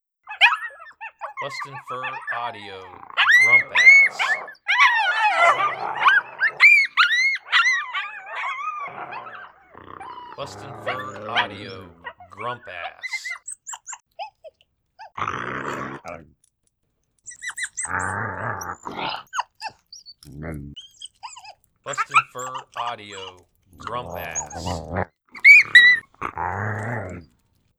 Adult male and female coyotes growling and snapping at each other.